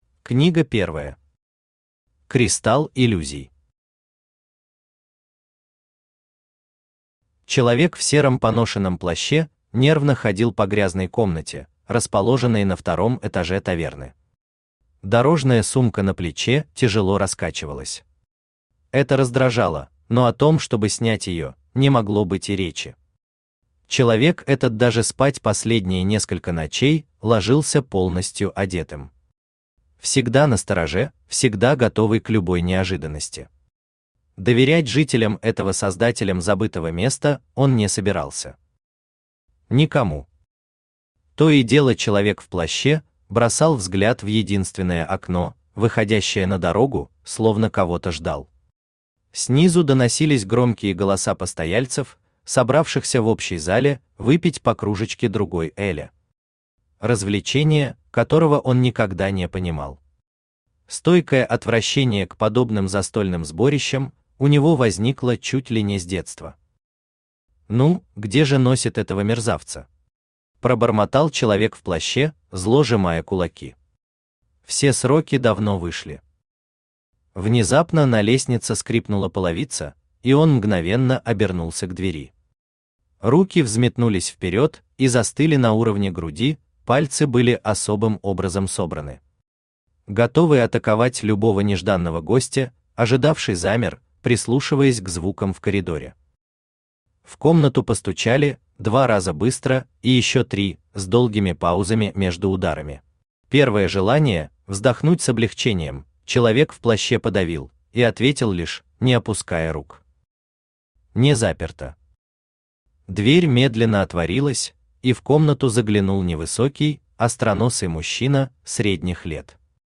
Аудиокнига Город Бессмертных. Трилогия | Библиотека аудиокниг
Трилогия Автор Даниэль Дессан Читает аудиокнигу Авточтец ЛитРес.